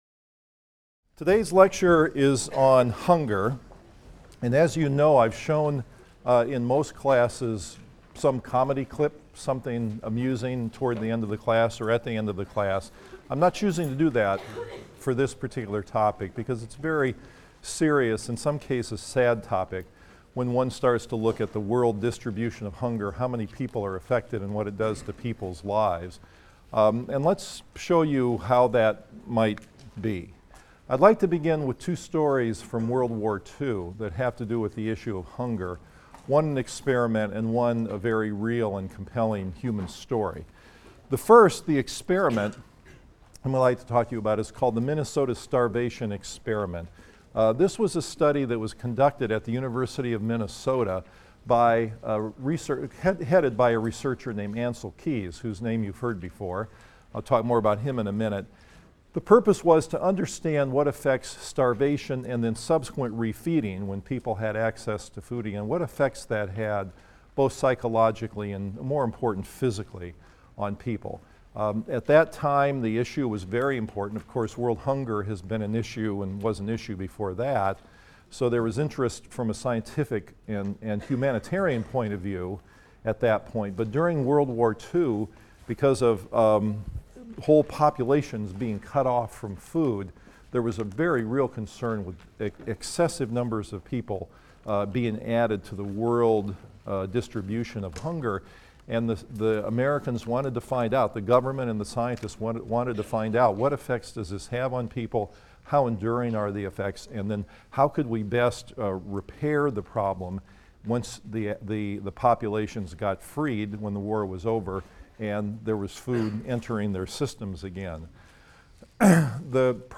PSYC 123 - Lecture 7 - Hunger in the World of Plenty | Open Yale Courses